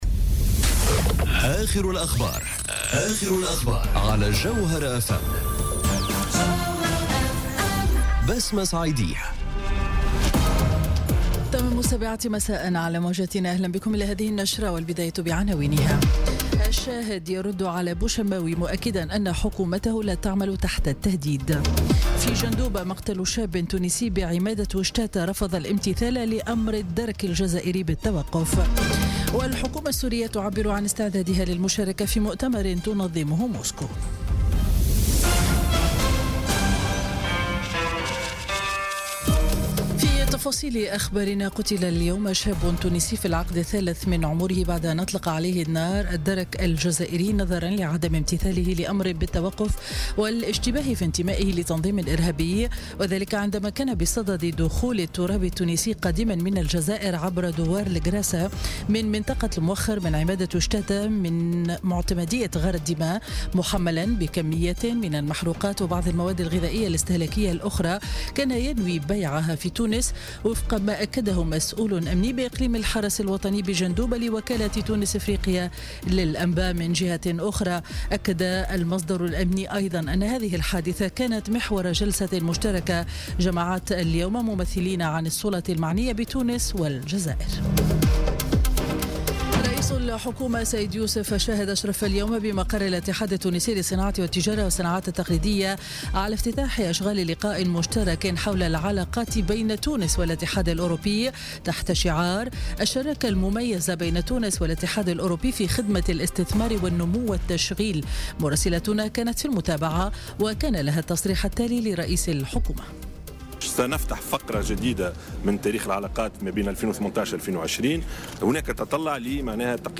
نشرة أخبار السابعة مساء ليوم الثلاثاء 31 أكتوبر 2017